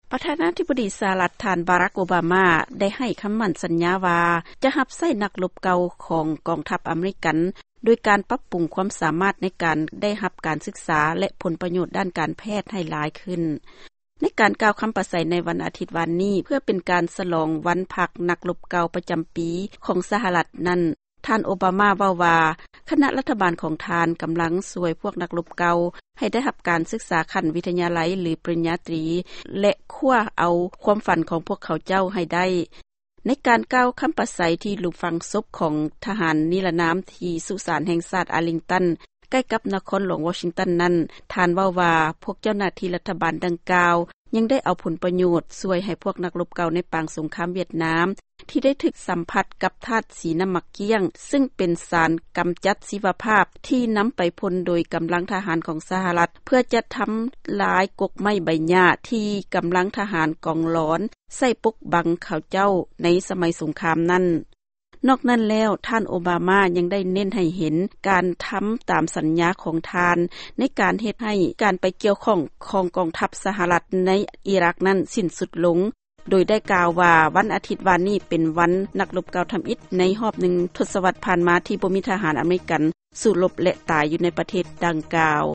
ຟັງຂ່າວຄໍາປາໄສຂອງທ່ານ ໂອບາມາໃນໂອກາດ ວັນນັກລົບເກົ່າ